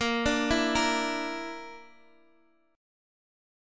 A#Mb5 chord